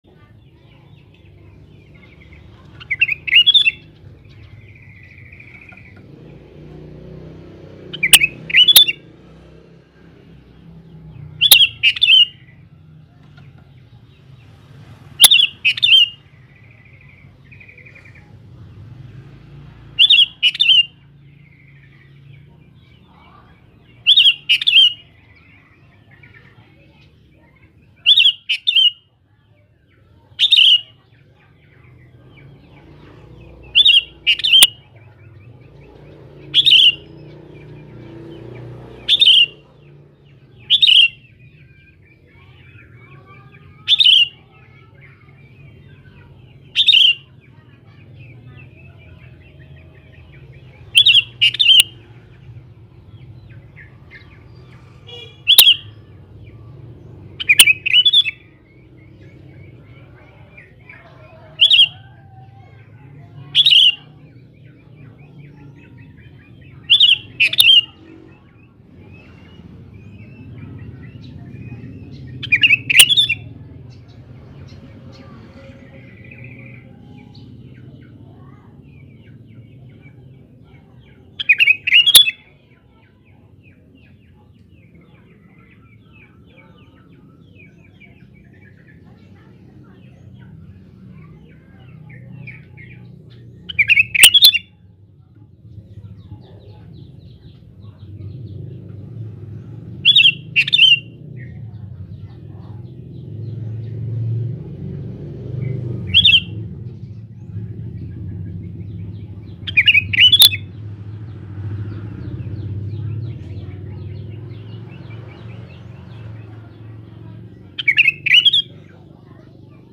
Suara Burung Kutilang Jantan
Kategori: Suara burung
Keterangan: Nikmati suara burung Kutilang jantan gacor yang dijamin bikin burung peliharaan Anda langsung nyaut!
Dengan kualitas suara jernih dan durasi yang pas, Mp3 ini akan membantu melatih burung Kutilang Anda agar semakin rajin berkicau.
suara-burung-kutilang-jantan-id-www_tiengdong_com.mp3